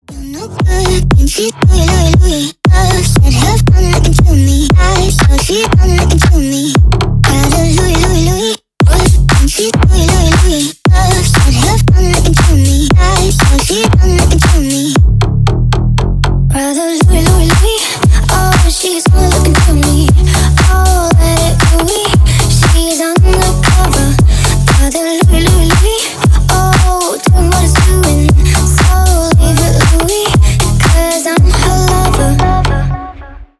Ремикс
Танцевальные
клубные